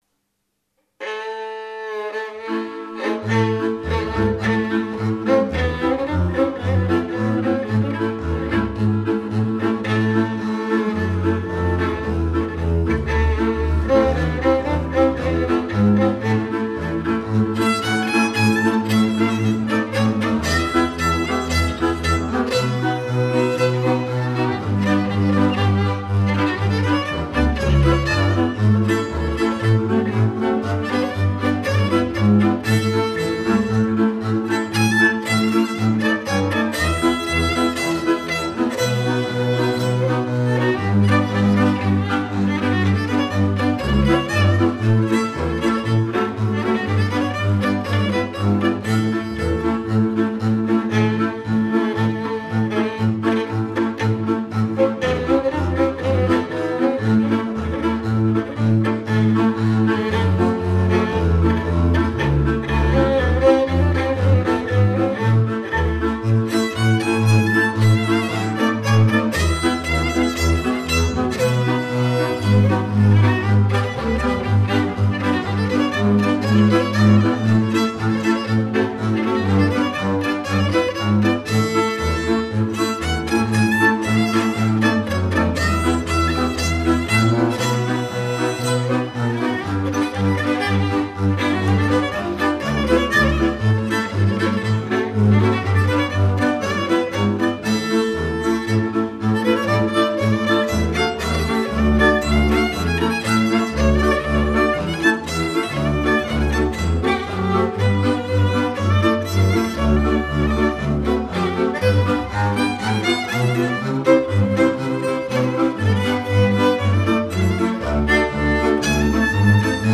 Ceardas – Csárdás (03:43)